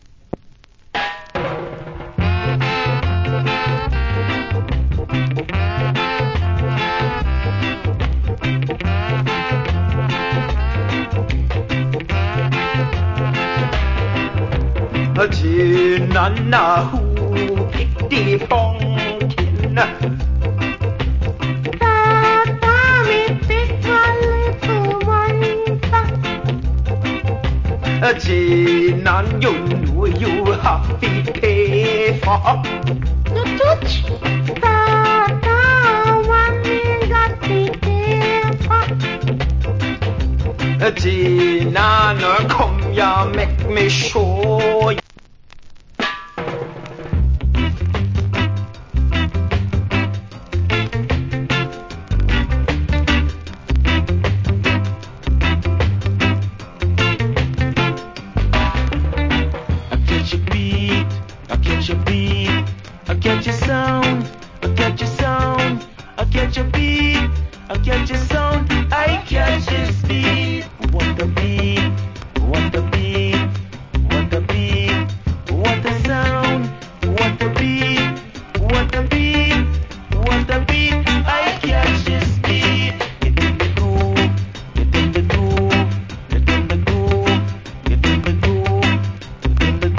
Good Early Reggae Vocal.